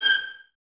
Index of /90_sSampleCDs/Miroslav Vitous - String Ensembles/23 Violins/23 VS Stacc